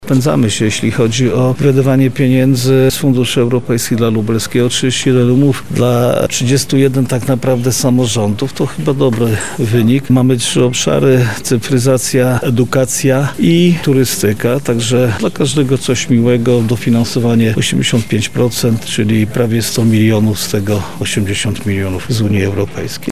– tłumaczył Jarosław Stawiarski, Marszałek Województwa Lubelskiego.